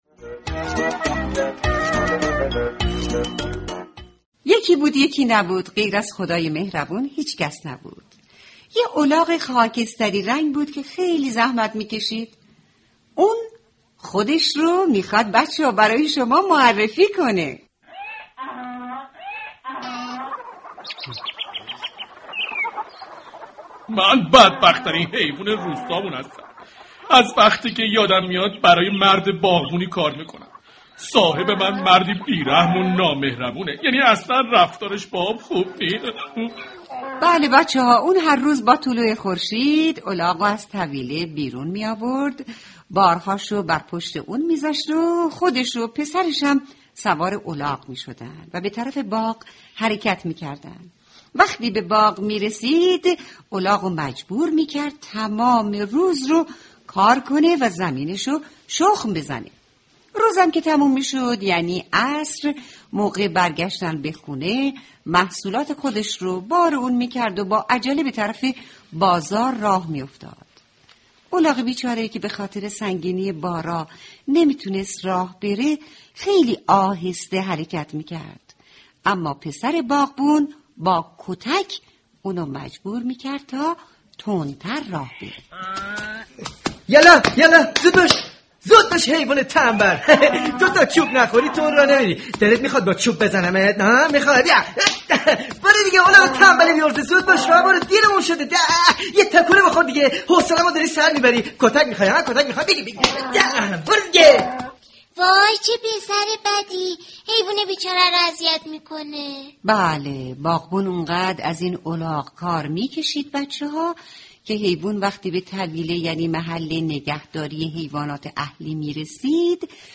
قصه صوتی کودکانه الاغ خاکستری